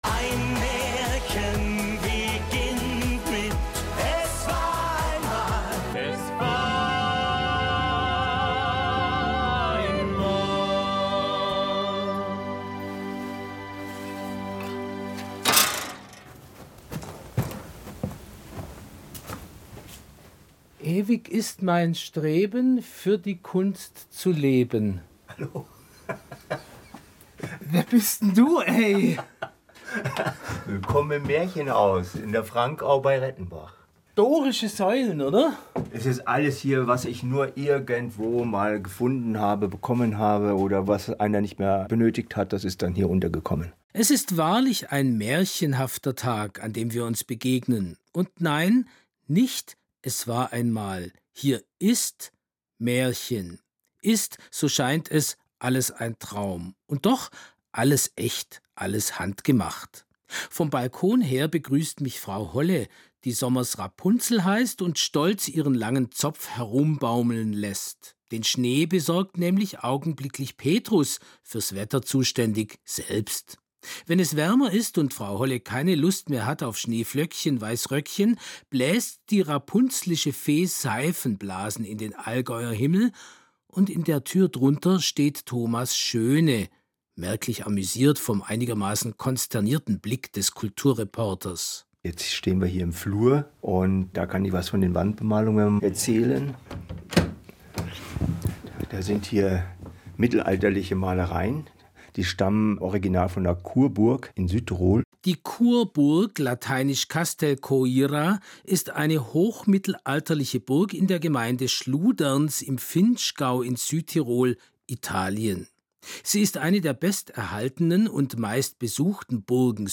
Sendung vom Bayerischen Rundfunk über mich und König Ludwig | Kunst Skulpturen Raumgestaltung Kunstmalerei Illusionsmalerei